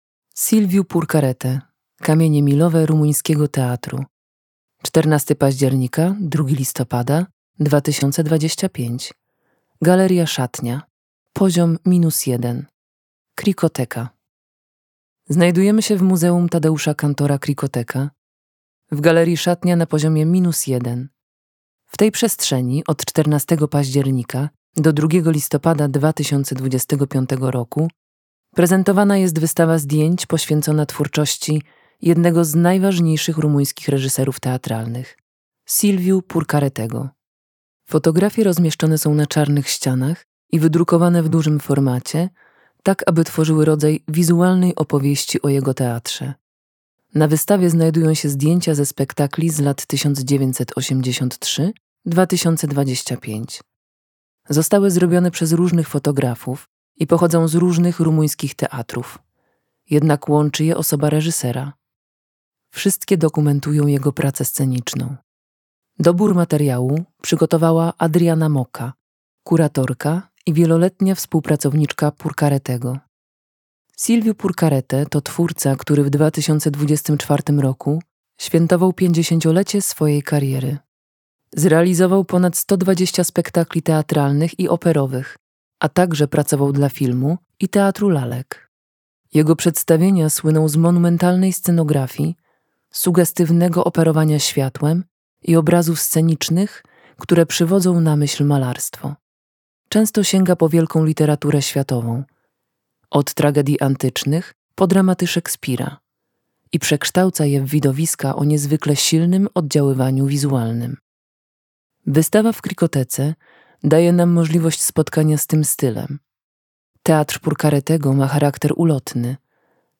Dla zwiedzających przygotowany został audiodeskryptywny wstęp oraz audiodeskrypcja pięciu wybranych obiektów: